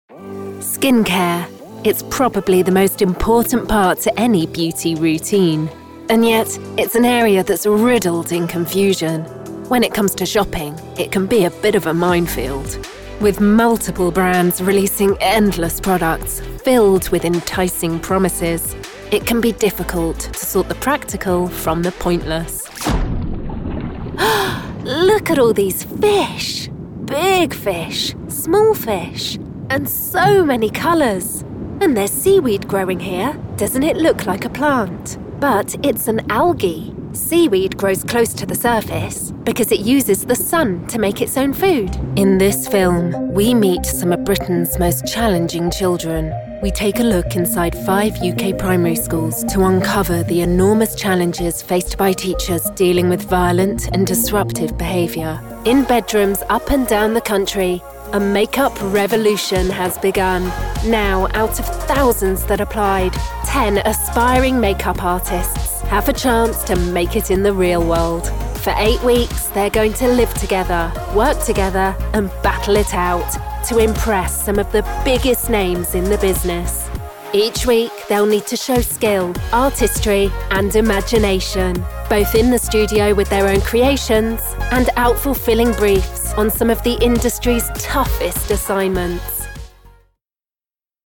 Documentales
Con un acento inglés neutro/RP, mi voz natural es brillante, atractiva y fresca. Se la ha descrito como limpia, segura y, lo más importante, ¡versátil!
Cabina de paredes sólidas hecha a medida con tratamiento acústico completo